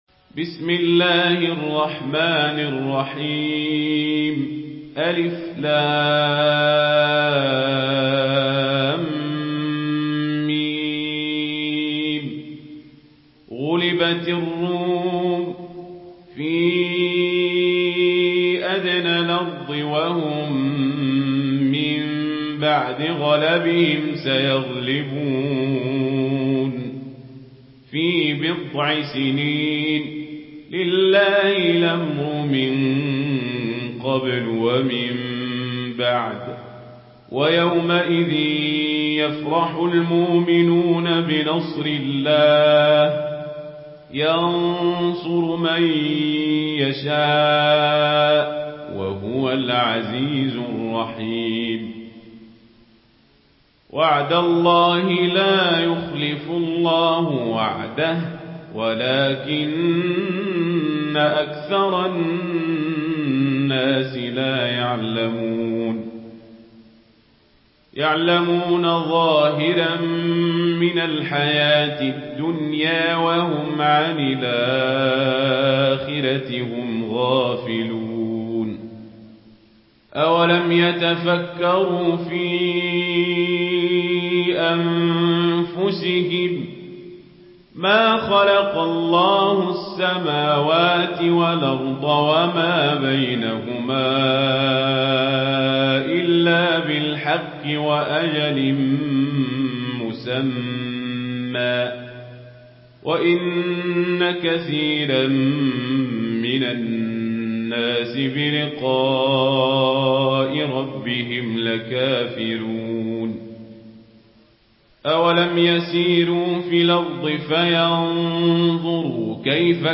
Surah الروم MP3 in the Voice of عمر القزابري in ورش Narration
Listen and download the full recitation in MP3 format via direct and fast links in multiple qualities to your mobile phone.
مرتل ورش عن نافع